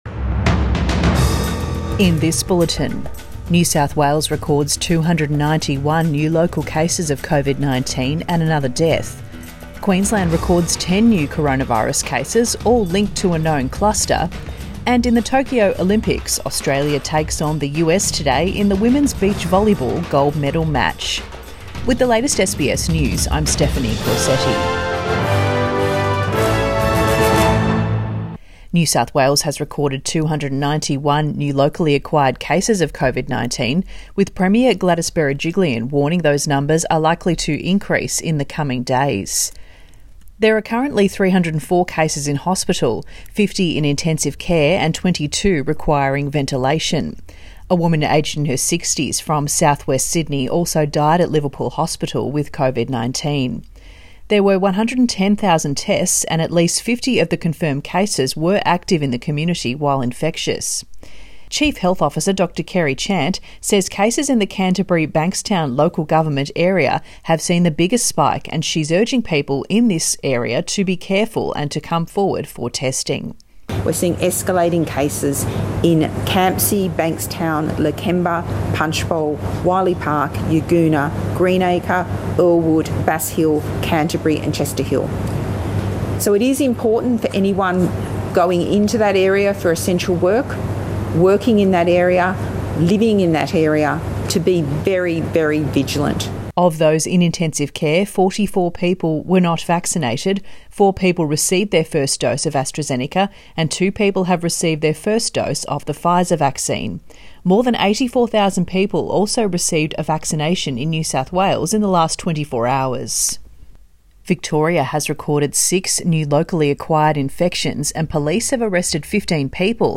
Midday bulletin 6 August 2021